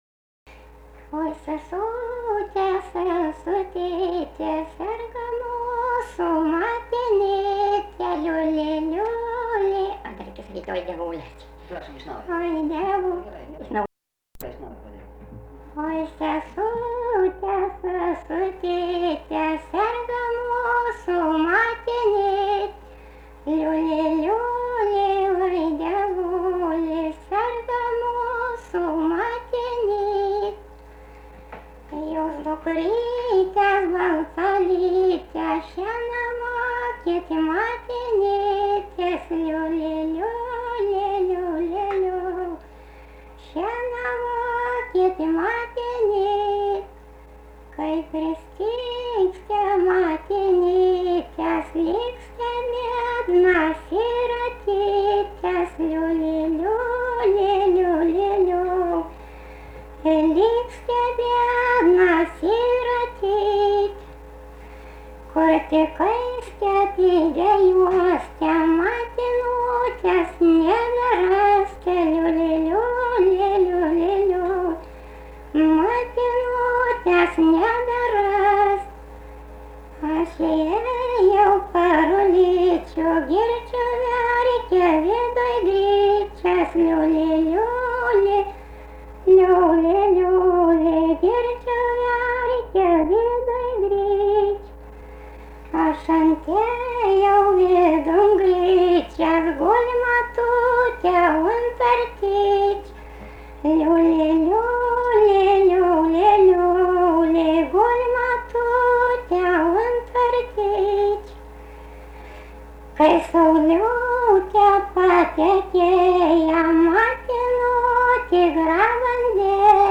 daina, karinė-istorinė